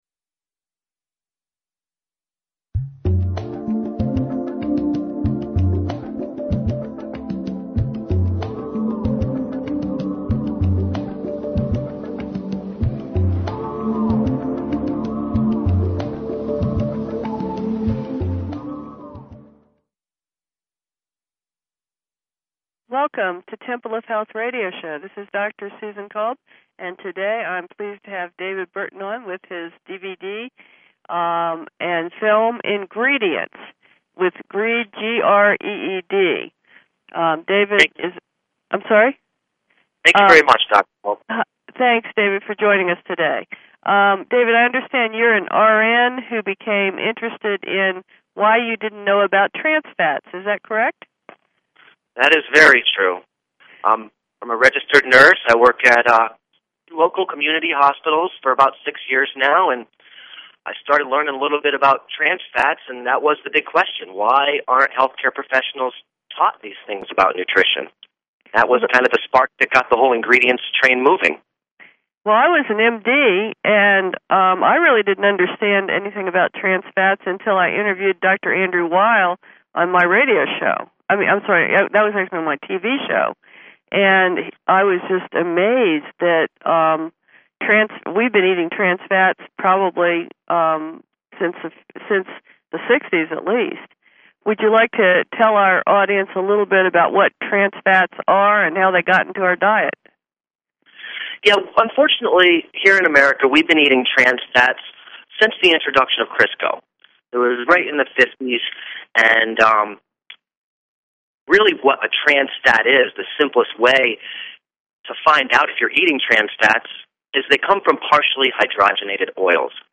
Each week we bring the audience a personalized educational experience with leading authors, national and local experts on the following topics: Traditional and Holistic Medicine Integrative Health Philosophies Spiritual Growth and Fulfillment Scientific Breakthroughs Various Medical Disorders Including: Diabetes, Cancer, Thyroid, Depression, Heart Disease, Arthritis, Alzheimer's, Nutrition, Auto Immune Diseases and Many More.